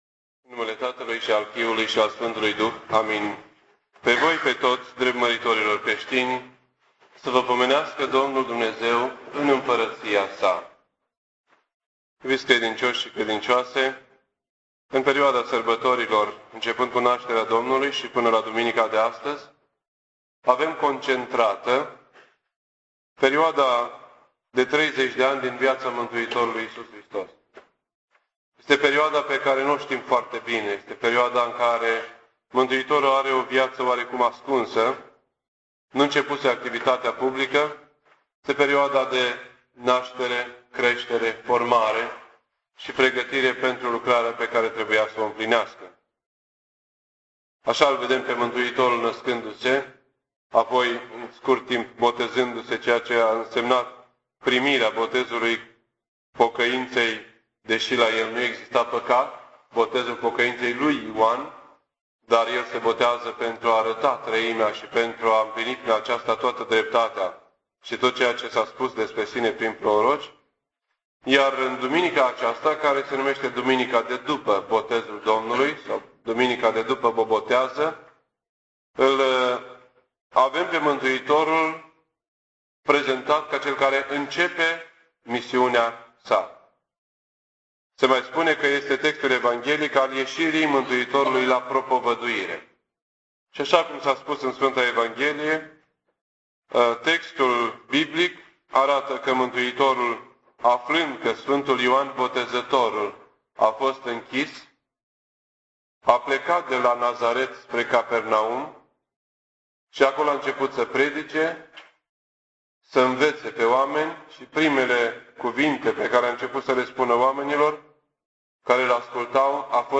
This entry was posted on Sunday, January 13th, 2008 at 9:52 AM and is filed under Predici ortodoxe in format audio.